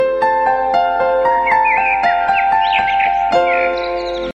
Красивое пение птиц